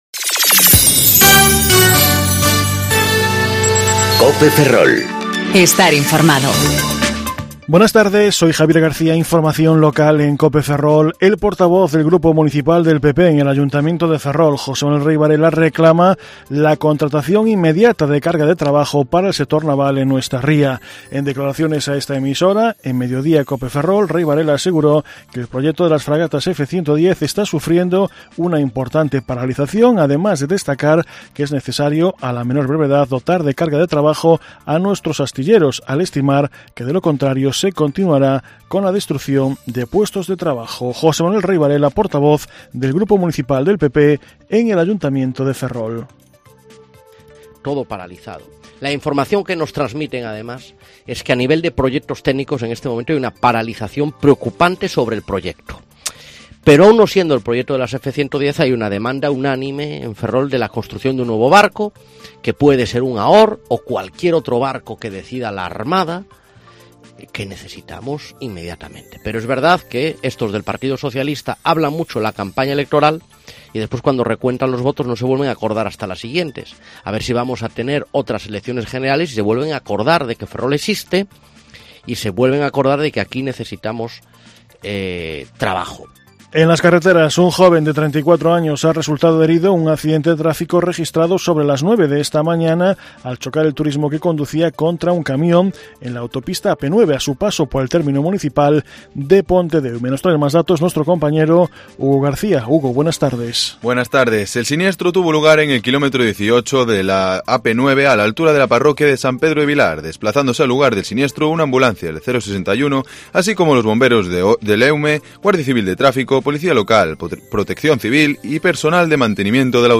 Informativo Mediodía Cope Ferrol 1/8/2019 (De 14.20 a 14.30 horas)